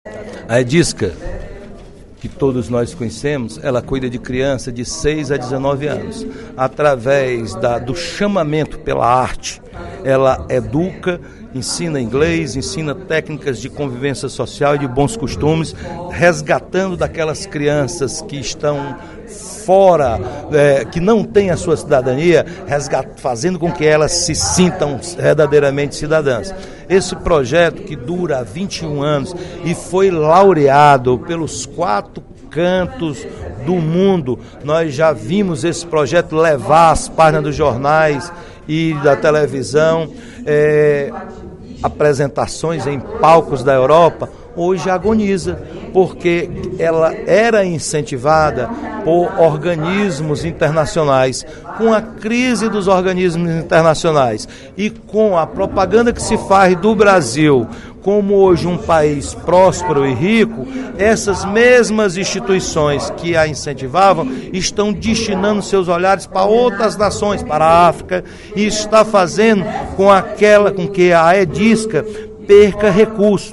O deputado Roberto Mesquita (PV) fez, nesta sexta-feira (15/06), pronunciamento na Assembleia Legislativa para defender a preservação da organização não governamental Edisca, que trabalha com crianças e adolescentes no bairro Panamericano, em Fortaleza.